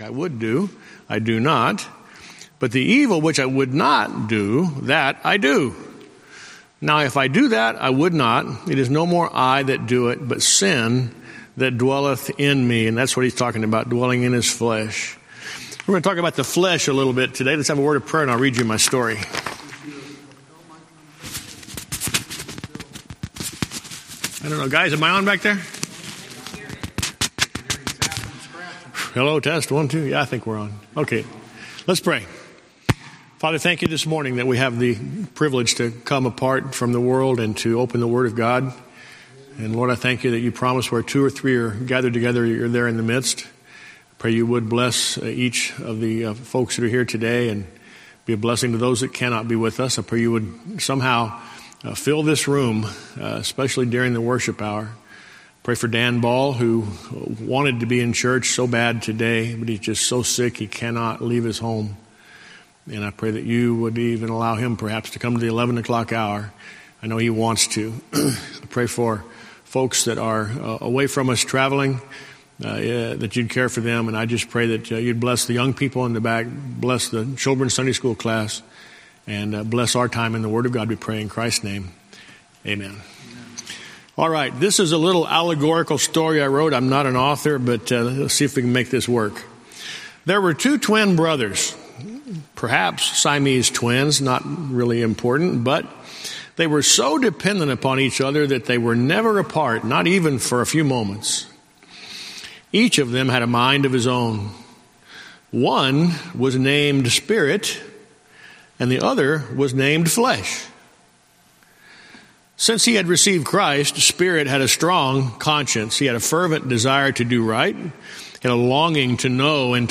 Sermons
Series: Guest Speaker